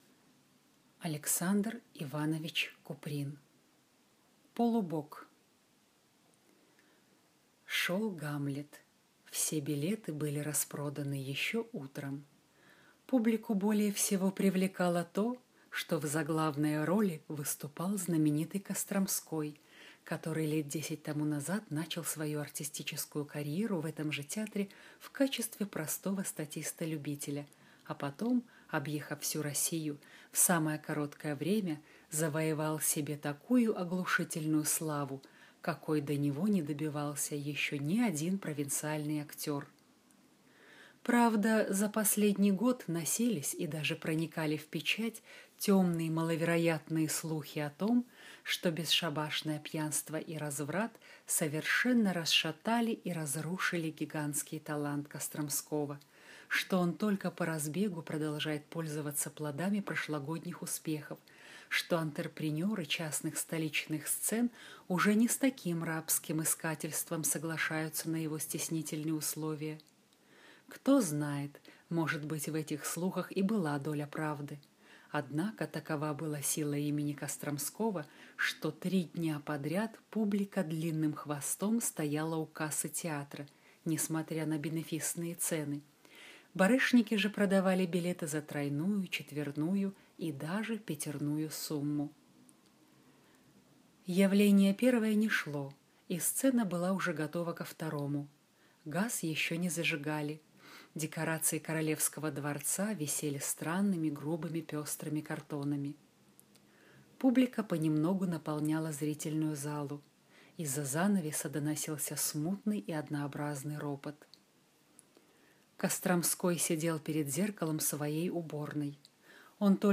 Аудиокнига Полубог | Библиотека аудиокниг